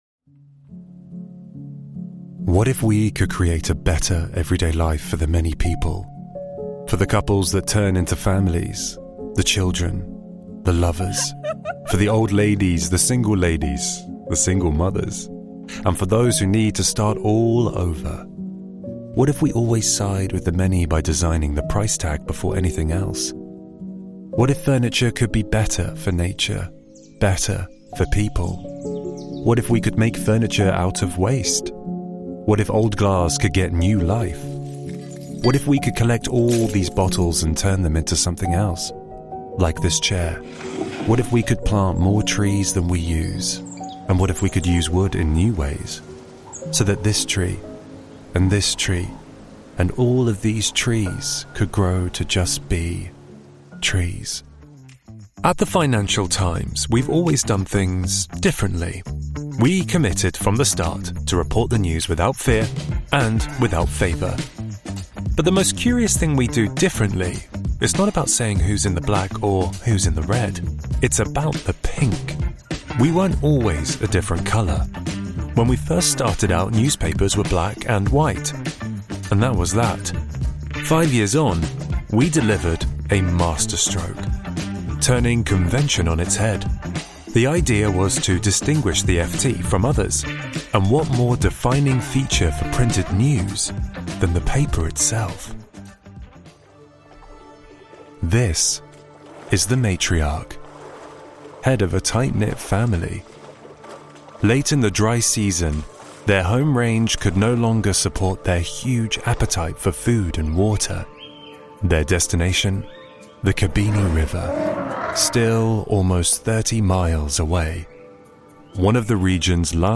Narração
BRITISH VOICE OVER ARTIST IN LONDON.
Nuemann U87 2024 / Nuemann TLM 193. Shure SM7B.
Sound proofed booth (Session Booth)